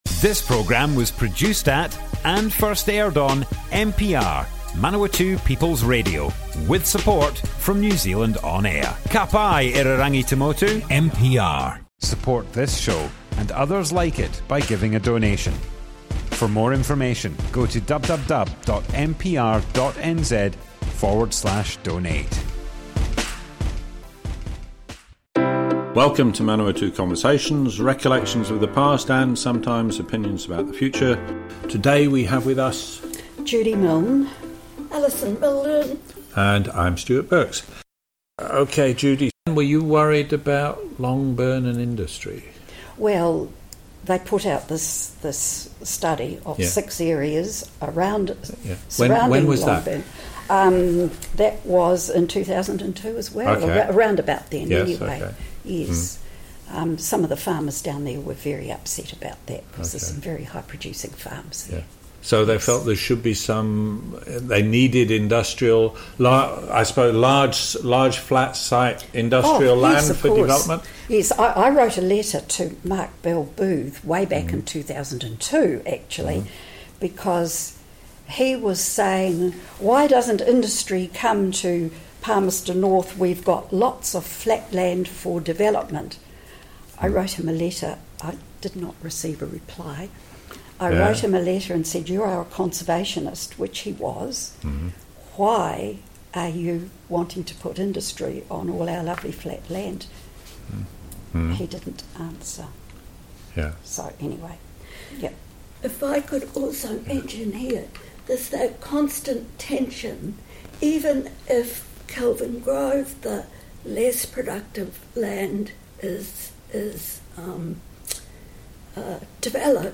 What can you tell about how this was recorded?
Manawatu Conversations More Info → Description Broadcast on Manawatu People's Radio, 21st December 2021. part 2 of 2.